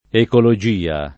ecologia [ ekolo J& a ] s. f.